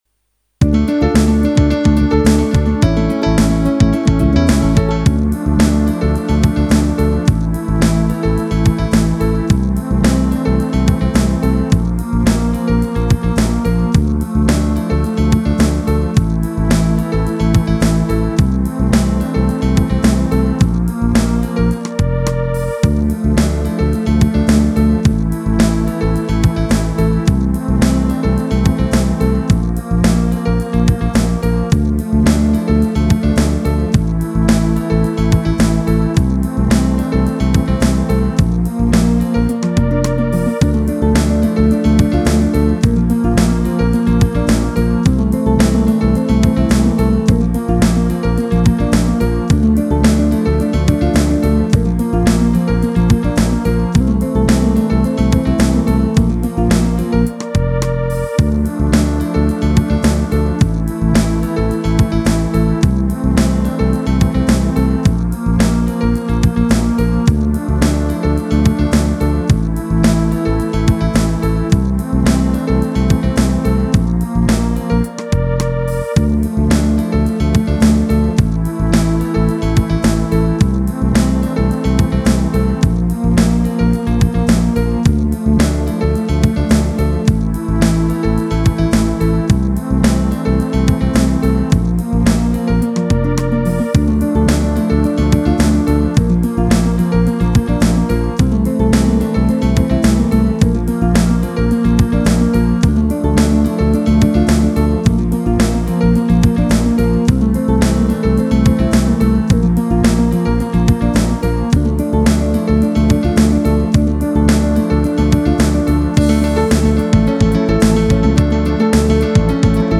Ik voeg steeds nieuwe ritmedemo's bovenaan toe, scroll dus naar beneden om alles te zien.
Akkoorden progressie ritme alleen.